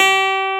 CLAV C4.wav